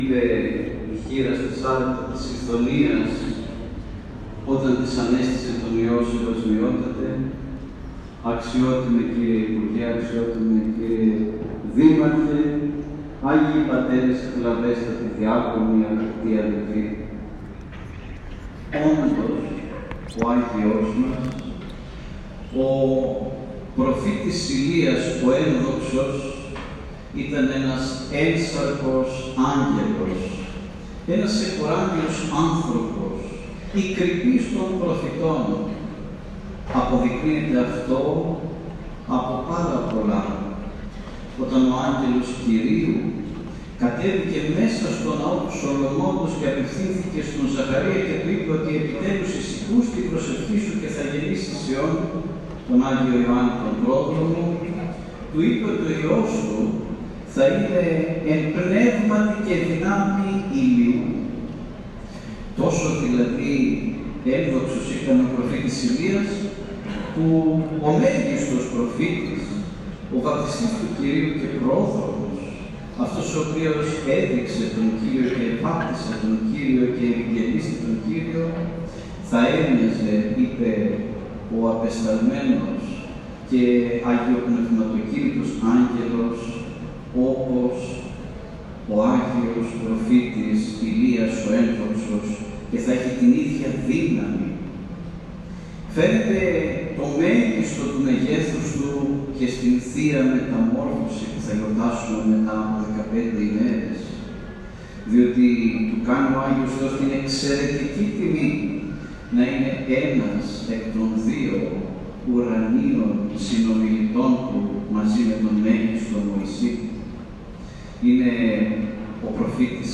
Με την ευλογία του Παναγιωτάτου Μητροπολίτου Θεσσαλονίκης κ. Ανθίμου, στην Ακολουθία του πανηγυρικού Αρχιερατικού Εσπερινού χοροστάτησε ο Σεβασμιώτατος Μητροπολίτης Τρίκκης και Σταγών κ. Χρυσόστομος πλαισιούμενος από τους Ιερείς και τους Ιεροδιακονους της Ι. Μητροπόλεως.
Ιερός-Ναός-Προφήτου-Ηλία-Πυλαίας.m4a